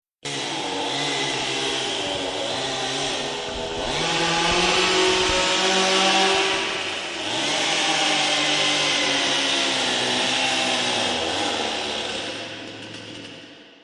Free SFX sound effect: Heavy Saw Echo.
Heavy Saw Echo
yt_L7O-xnDmMBM_heavy_saw_echo.mp3